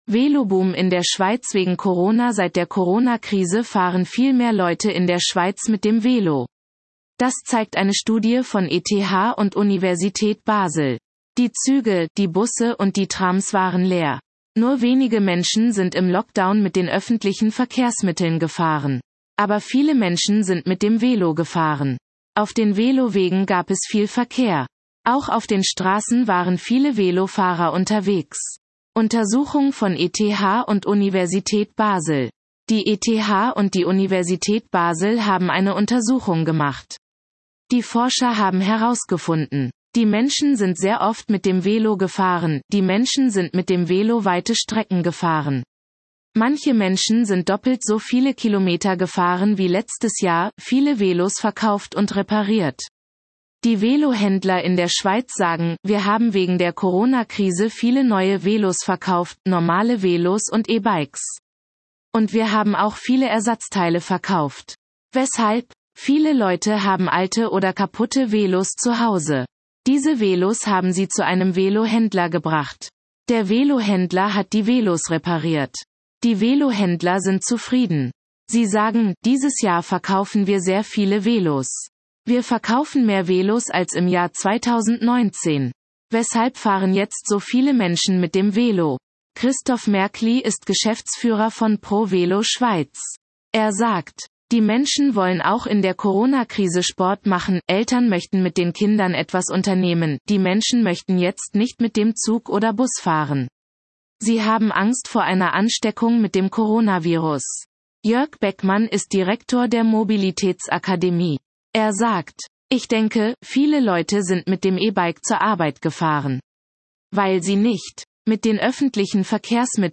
Vorlesen